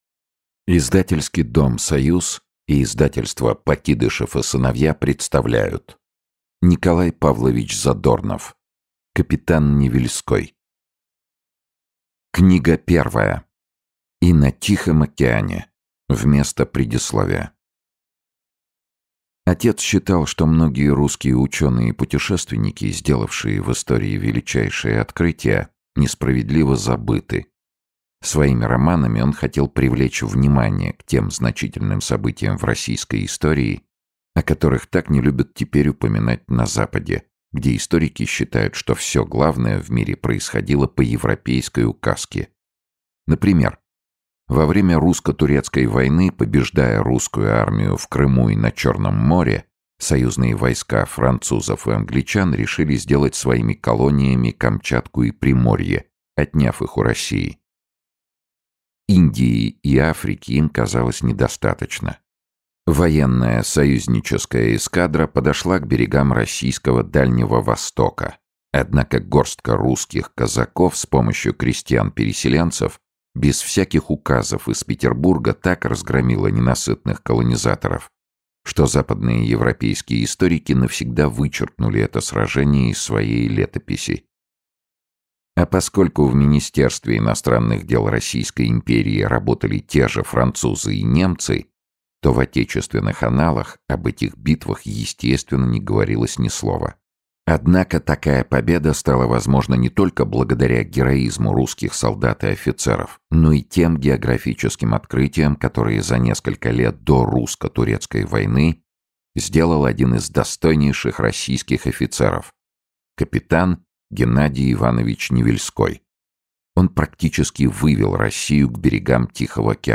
Aудиокнига Капитан Невельской Автор Николай Задорнов Читает аудиокнигу Сергей Чонишвили.